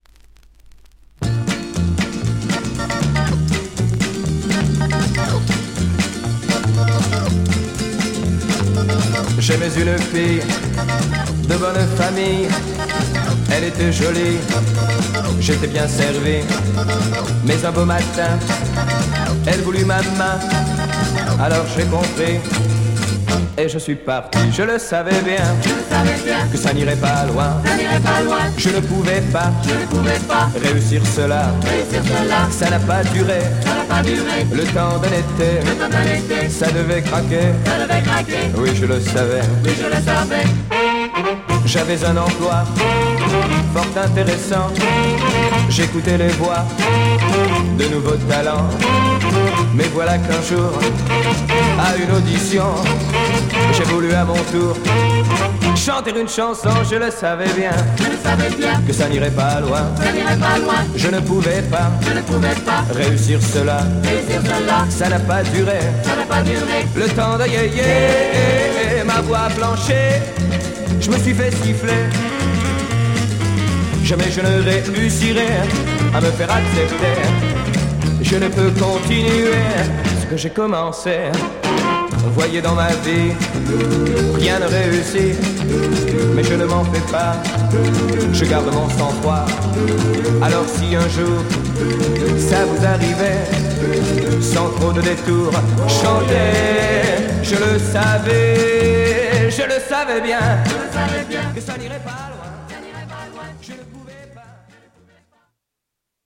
French Popcorn Yéyé